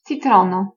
Ääntäminen
IPA : /ˈlɛmən/